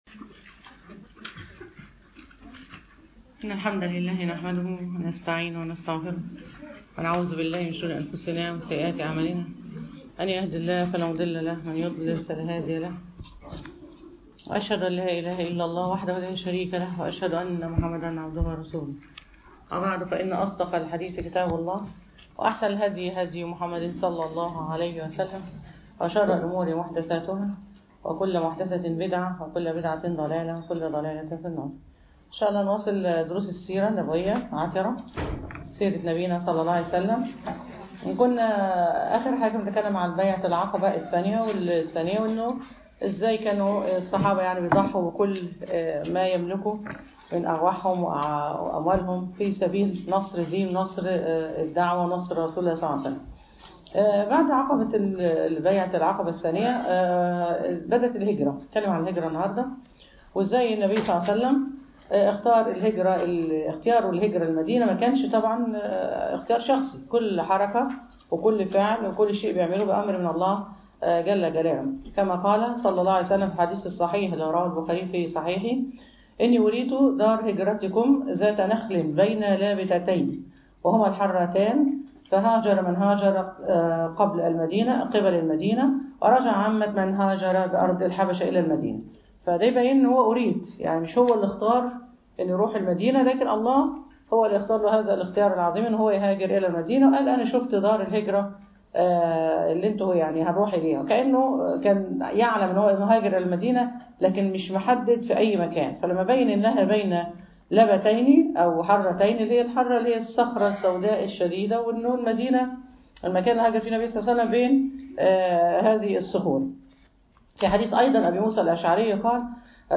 سيرة النبي ﷺ_المحاضرة الرابعة عشر